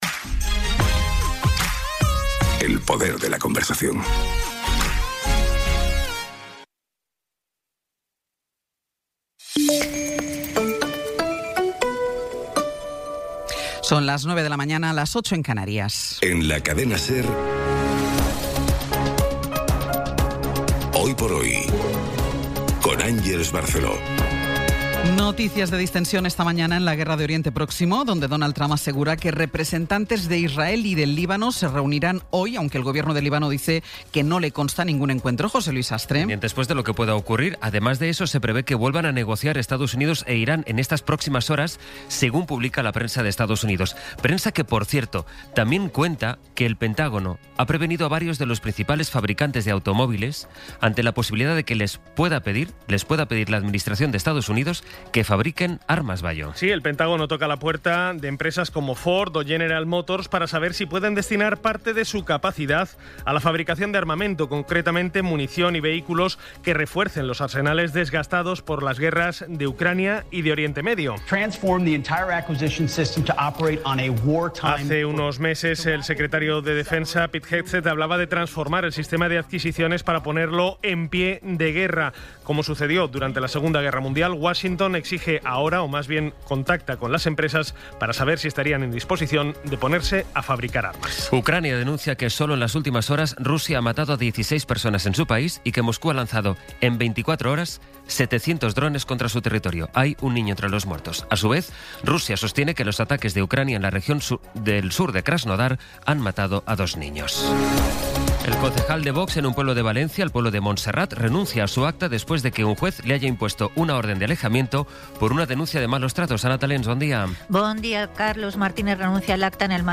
Resumen informativo con las noticias más destacadas del 16 de abril de 2026 a las nueve de la mañana.